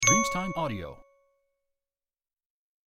Nuovo scintillio del carillon di notifica del messaggio
• SFX